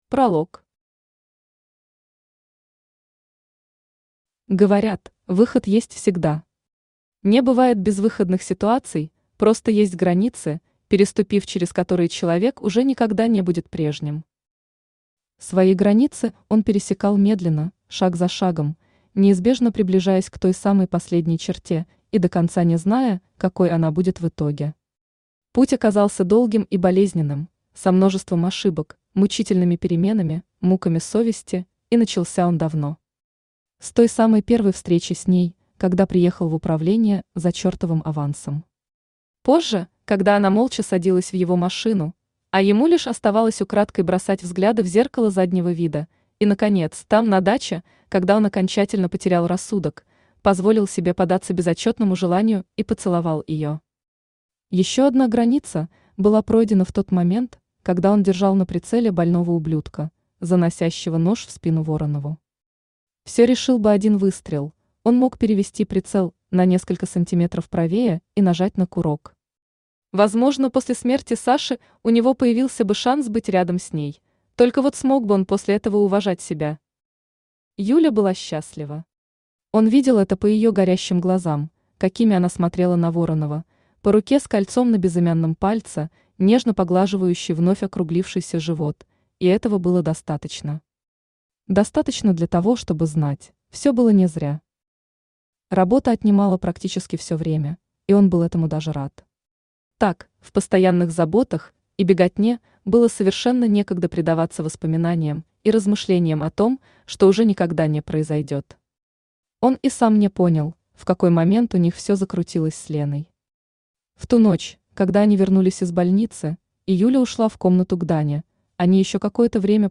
Аудиокнига Добро пожаловать в прошлое!
Автор Александра Ронис Читает аудиокнигу Авточтец ЛитРес.